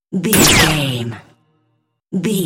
Dramatic stab laser shot energy
Sound Effects
heavy
intense
dark
aggressive
hits